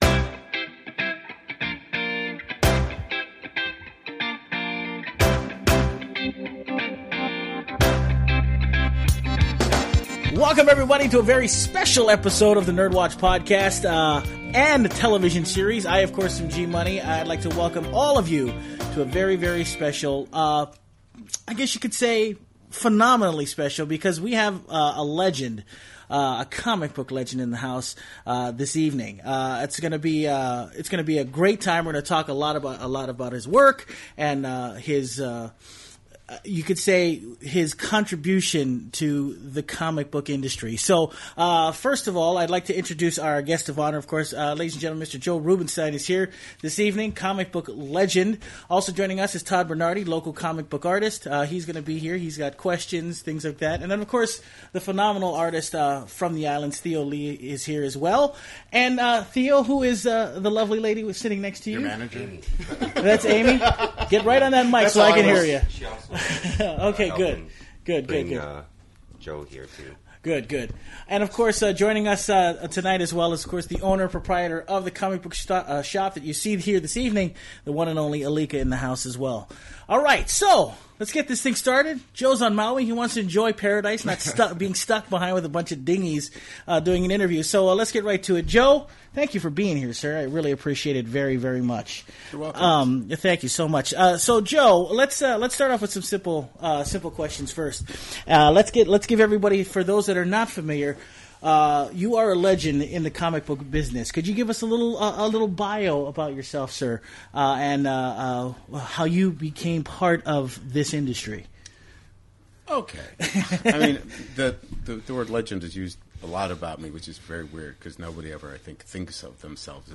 In this special podcast, the NERDWatch interviews comic book legend, Joe Rubinstein reflects about his life, his work, and the artists and storytellers he's worked with throughout his career including Stan Lee and Will Eisner. He also discusses his influence on the media of comic books and its artists.